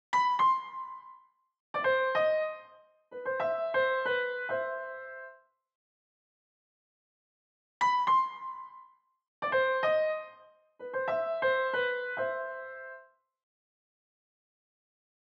piano FIN.wav